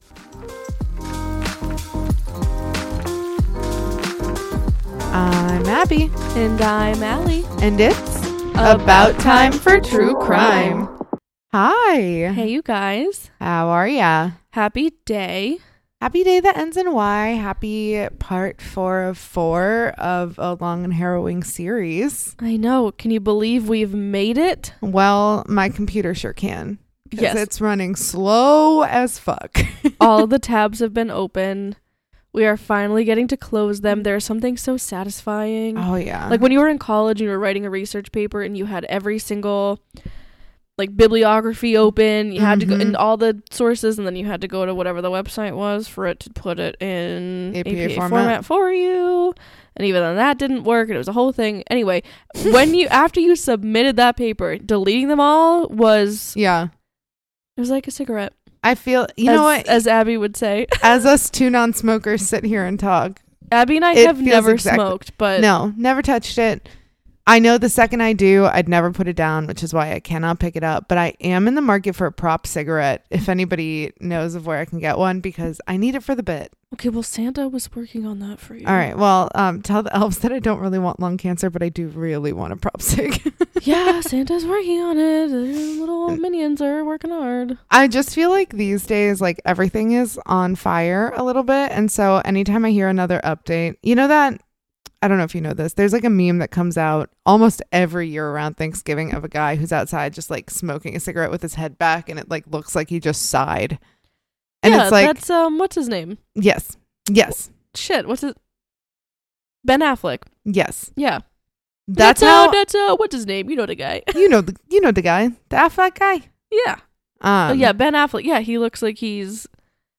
On 26 September 2025 Cambridge Women in Law (CWIL) hosted the Right Honourable Lady Arden of Heswall DBE as she chaired a compelling discussion with four exceptional legal minds shaping the future of human rights law